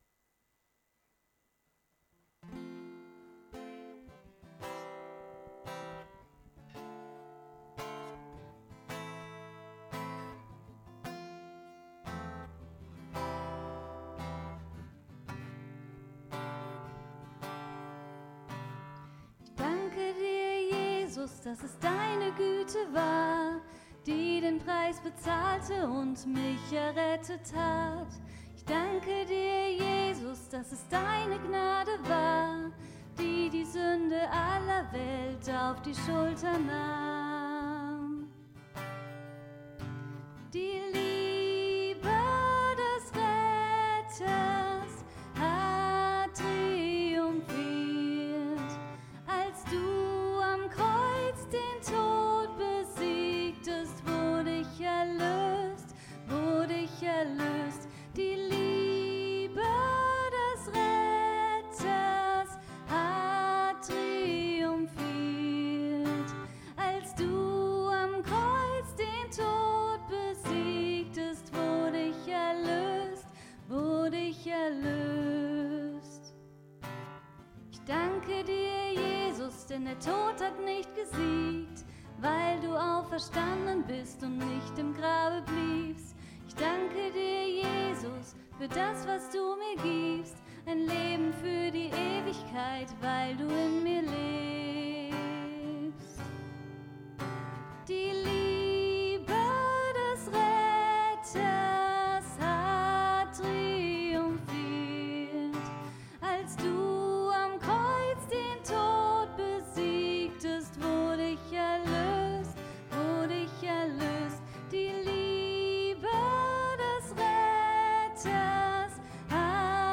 Andacht , Predigt